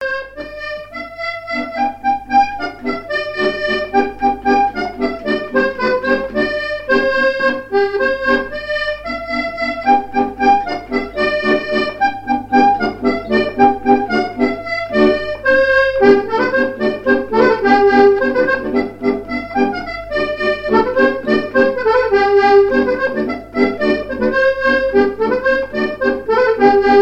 Mémoires et Patrimoines vivants - RaddO est une base de données d'archives iconographiques et sonores.
airs de danse à l'accordéon diatonique
Pièce musicale inédite